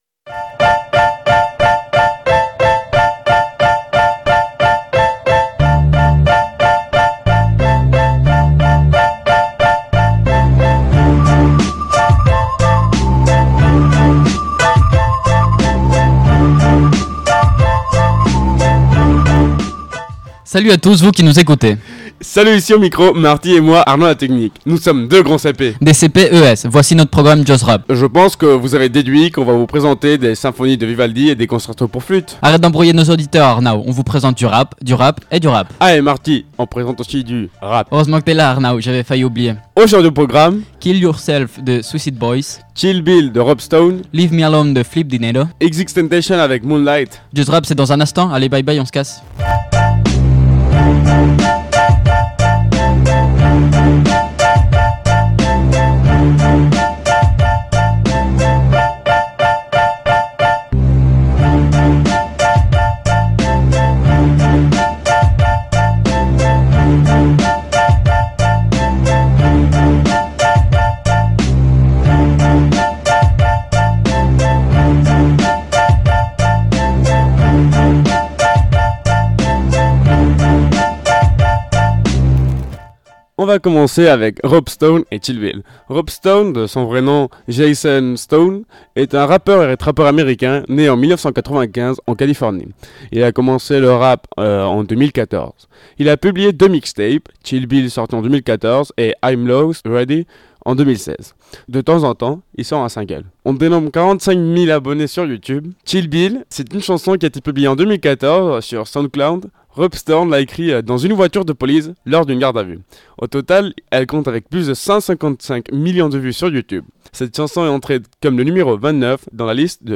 JUST RAP